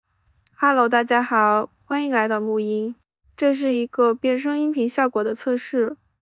原声：